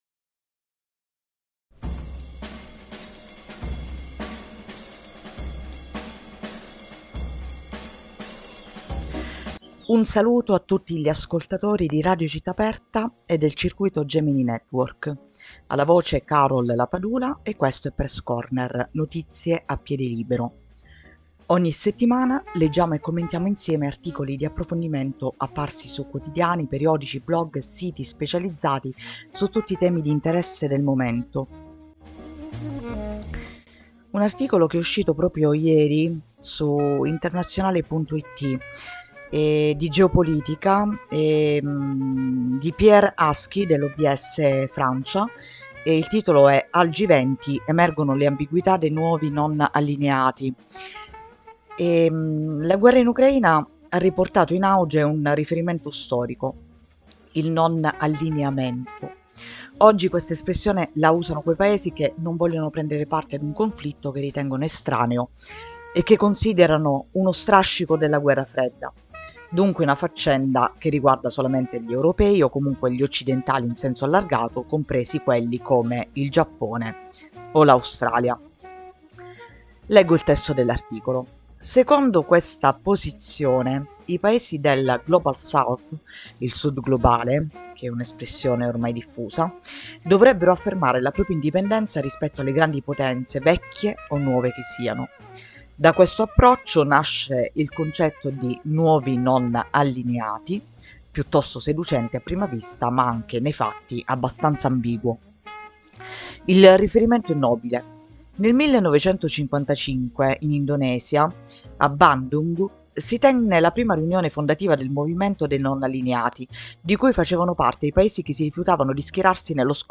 Leggiamo e commentiamo insieme articoli di approfondimento apparsi su quotidiani,